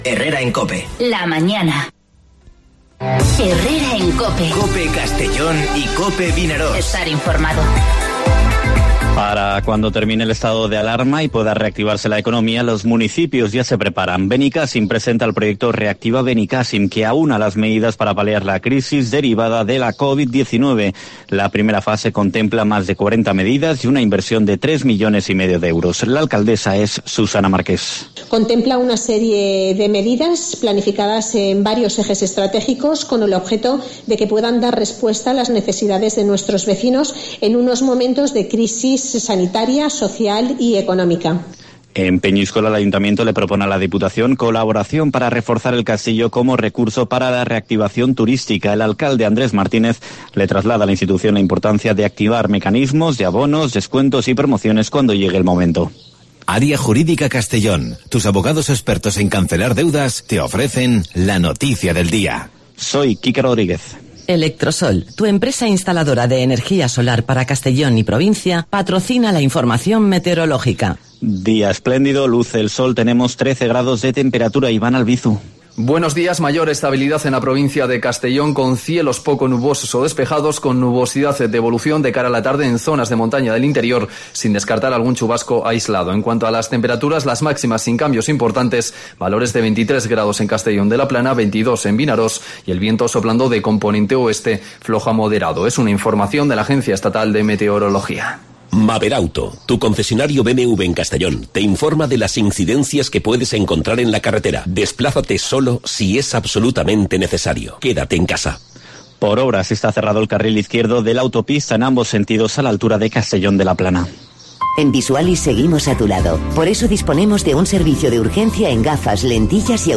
Informativo Herrera en COPE en la provincia de Castellón (28/04/2020)